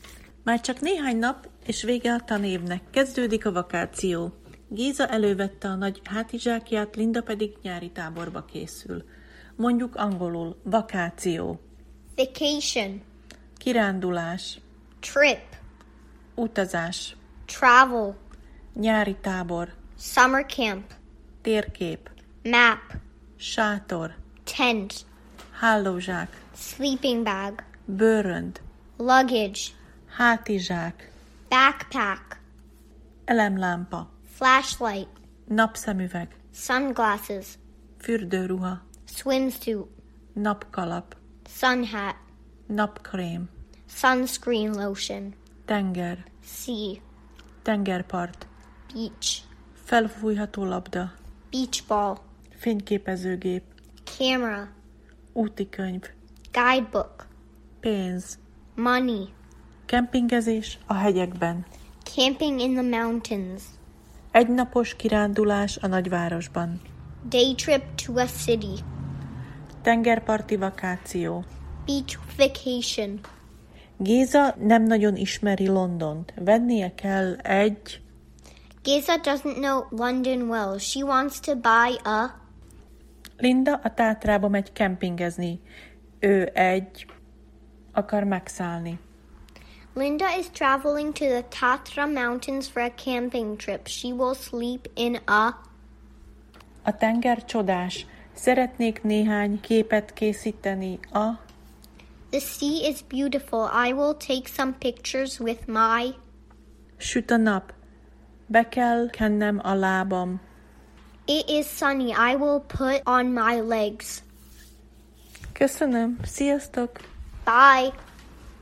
Angol lecke Géza gólyával 50. rész